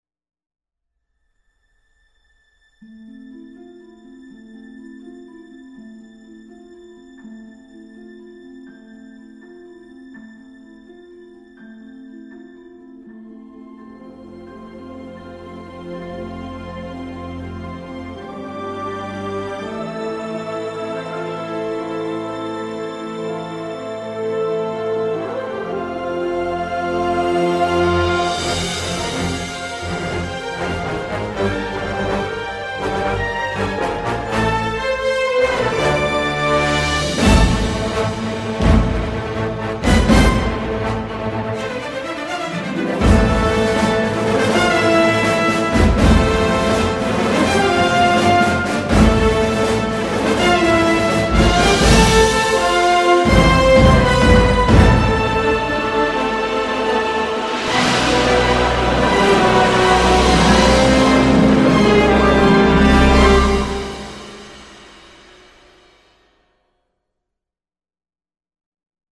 Épique à souhait